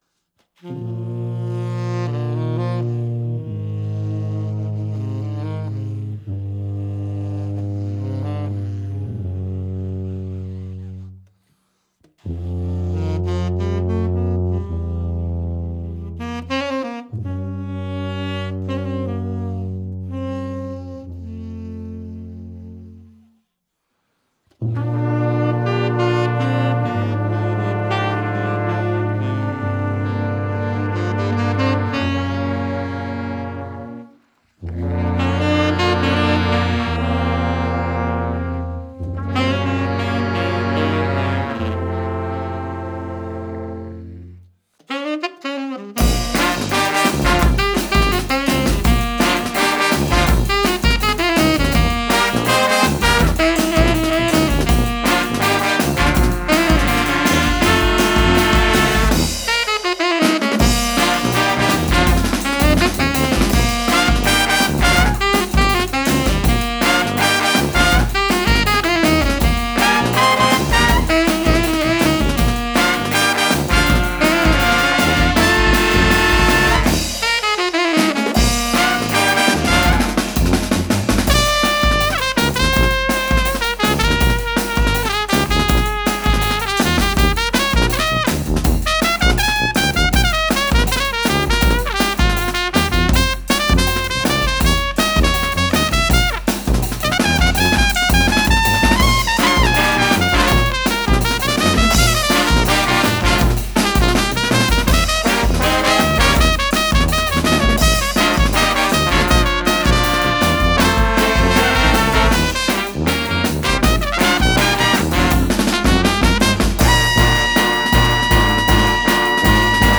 • Acoustique